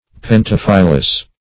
Search Result for " pentaphyllous" : The Collaborative International Dictionary of English v.0.48: Pentaphyllous \Pen*taph"yl*lous\, a. [Penta- + Gr.